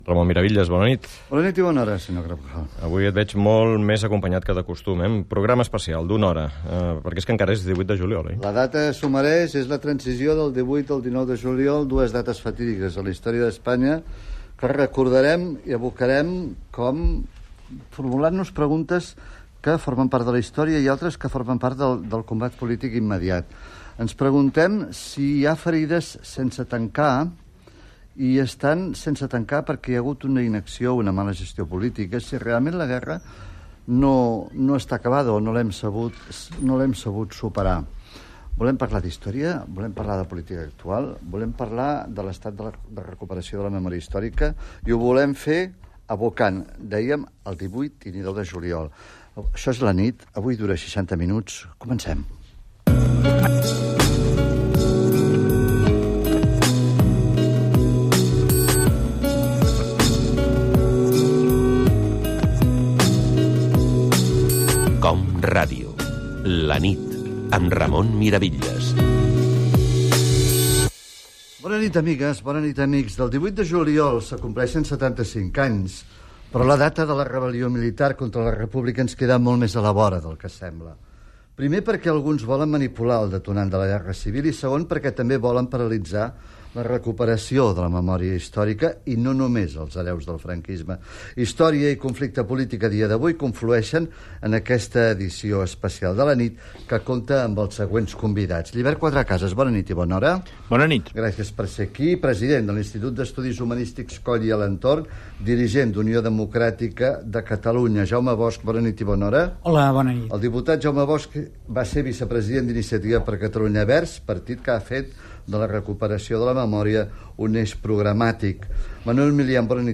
Presentació, careta, es compleixen 75 anys de l'inici de la Guerra Civil espanyola.
Informatiu
FM